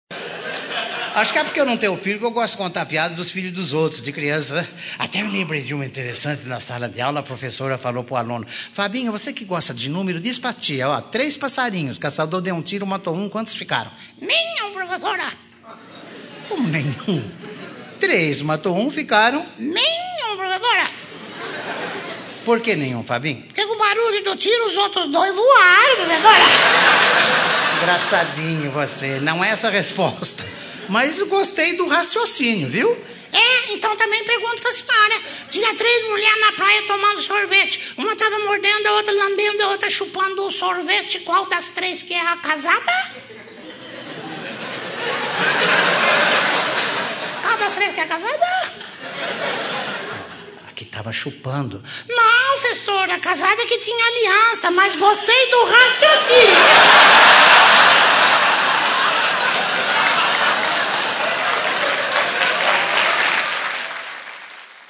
Piada em show ao vivo do comediante Ary Toledo.